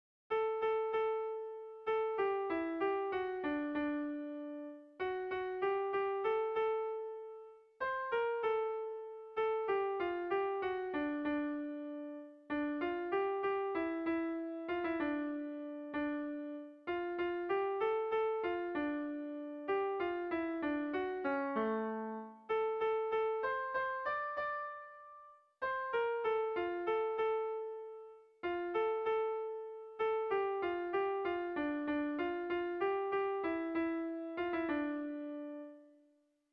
Bertso melodies - View details   To know more about this section
Sentimenduzkoa
A1A2BDA2